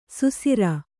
♪ susira